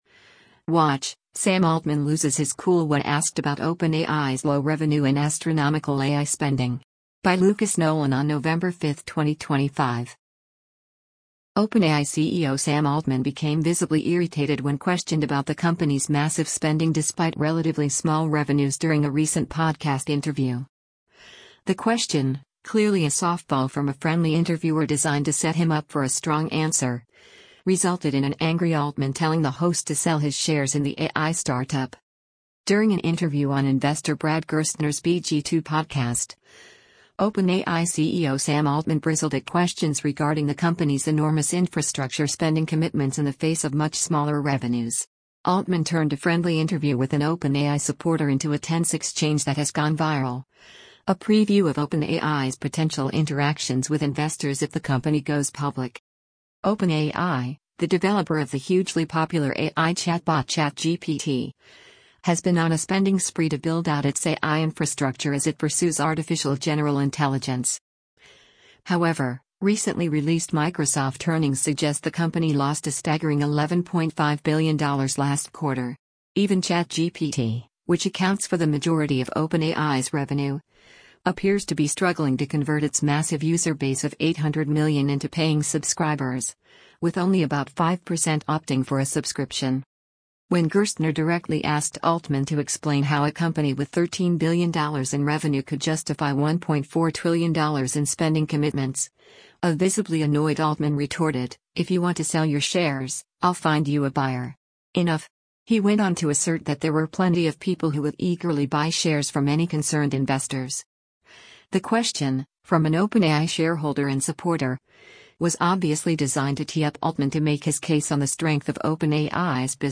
During an interview on investor Brad Gerstner’s BG2 podcast, OpenAI CEO Sam Altman bristled at questions regarding the company’s enormous infrastructure spending commitments in the face of much smaller revenues.
When Gerstner directly asked Altman to explain how a company with $13 billion in revenue could justify $1.4 trillion in spending commitments, a visibly annoyed Altman retorted, “If you want to sell your shares, I’ll find you a buyer. Enough.”